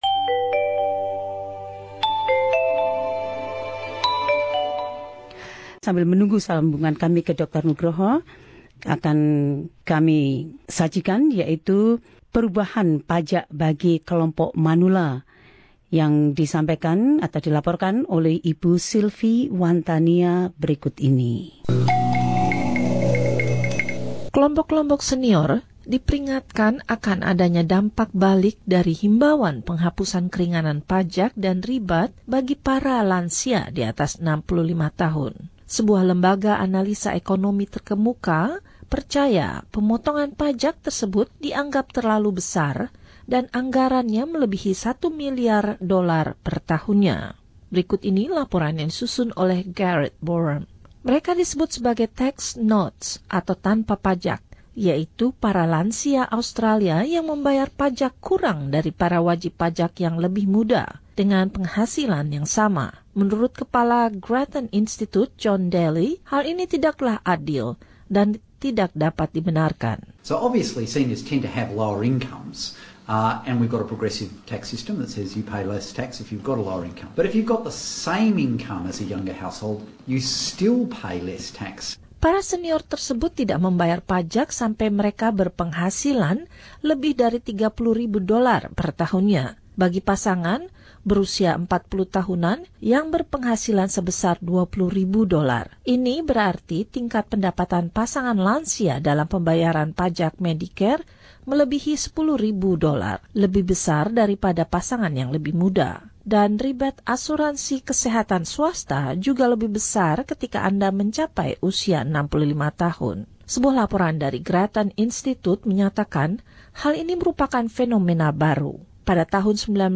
Laporan ini menyimak hal-hal tersebut.